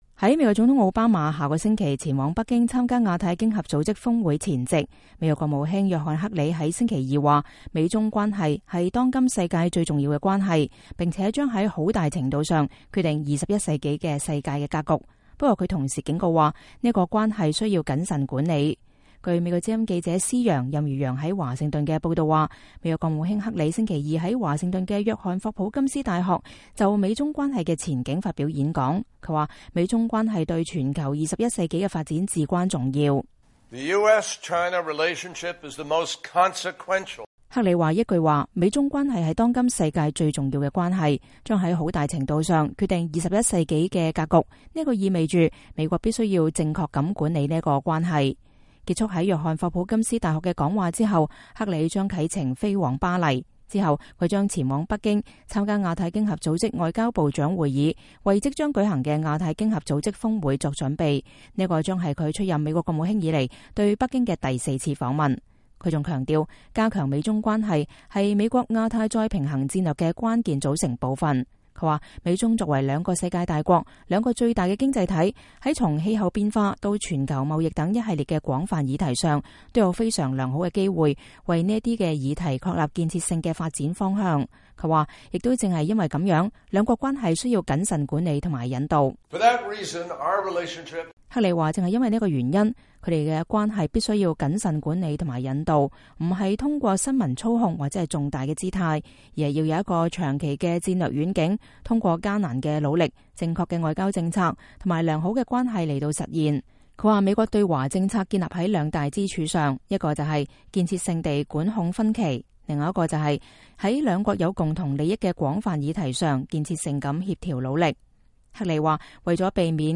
美國國務卿克里星期二在華盛頓的約翰•霍普金斯大學就美中關係的前景發表演講。